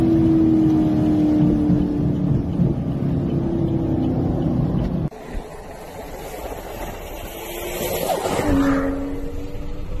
Kling-Audio-Eval / Sounds of other things /Engine /audio /27917.wav